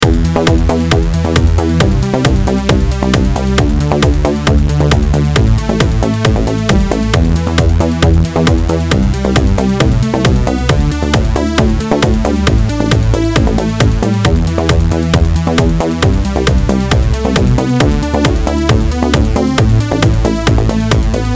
eurodance-mp3.6991